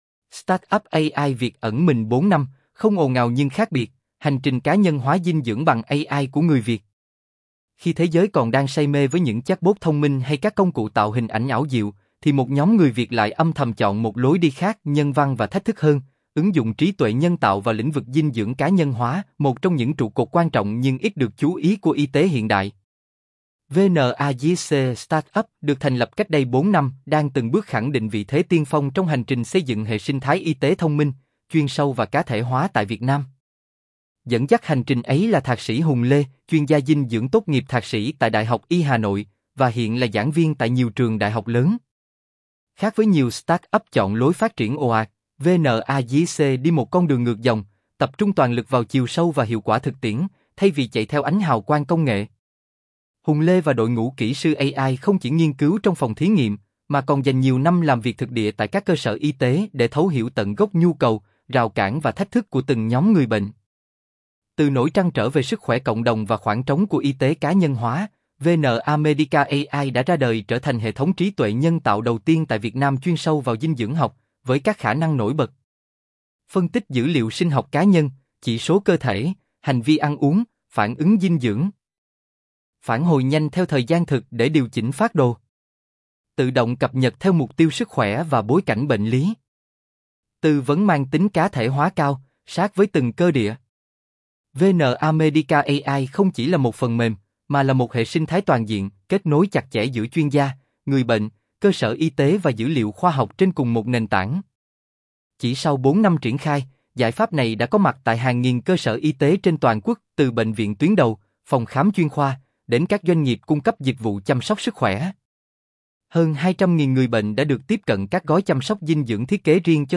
giong-nam-tone-nhe.mp3